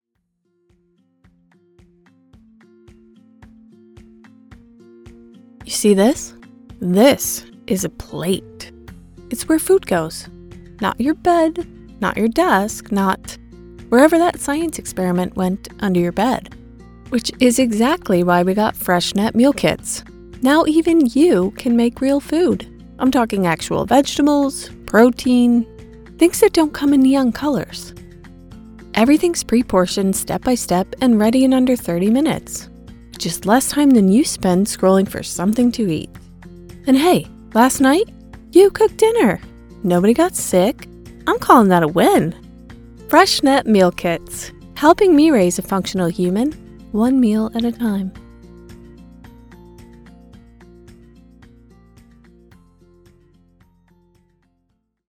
Professional Voice, Personality Included - Female Voice actor with chill vibes, a clear voice with adaptability for your project!
Beleivable Conversational Commercial Read Mom role
Middle Aged